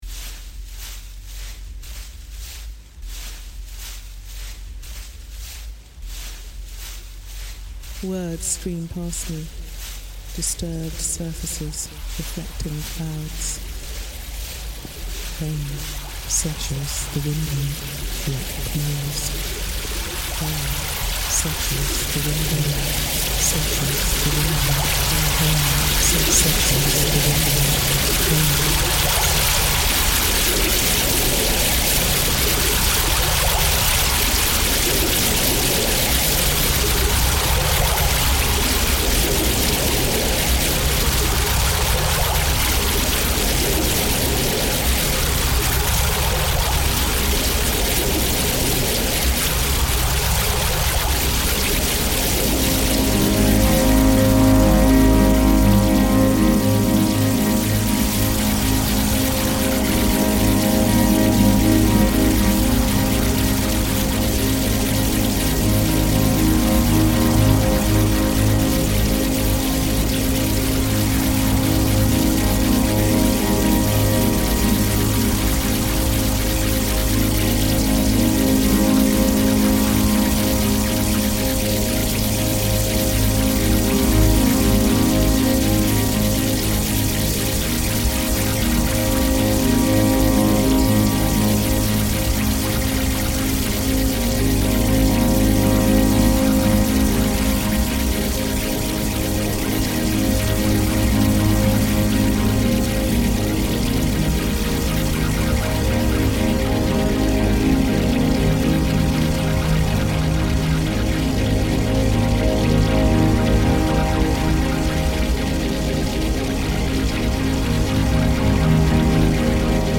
Lake District soundscape reimagined